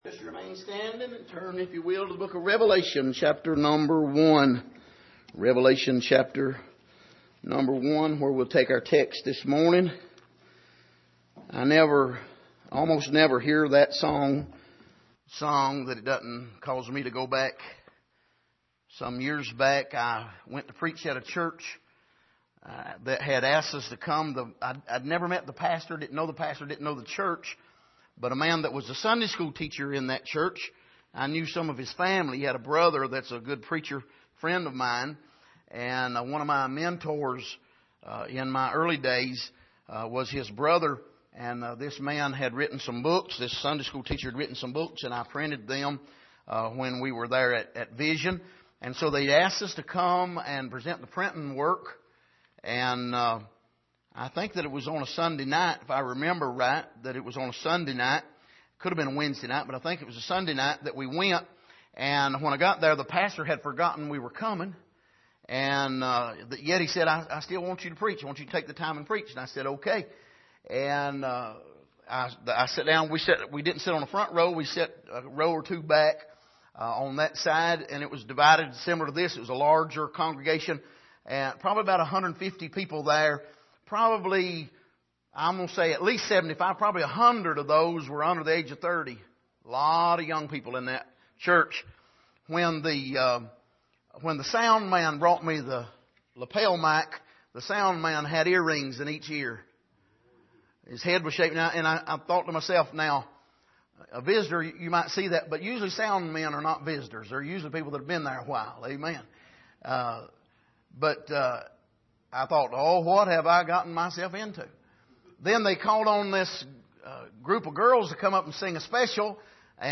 Passage: Revelation 1:9-11 Service: Sunday Morning